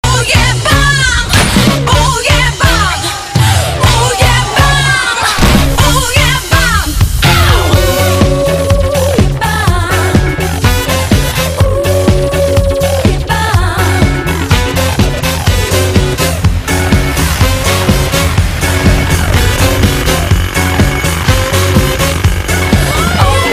• Качество: 320, Stereo
женский вокал
матерные